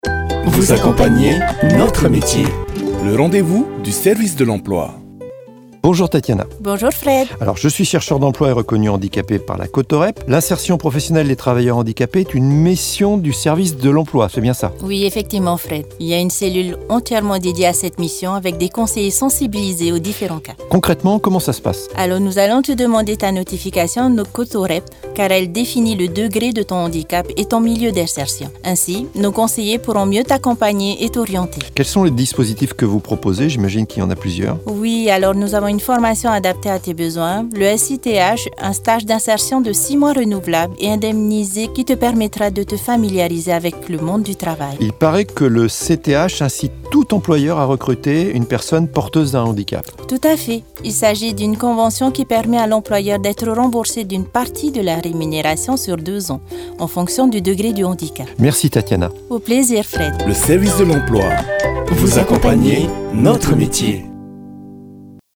ITV-SERVICE-DE-LEMPLOI-API-FM-020-SITH-N°1.mp3